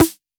Index of /musicradar/retro-drum-machine-samples/Drums Hits/WEM Copicat
RDM_Copicat_MT40-Snr01.wav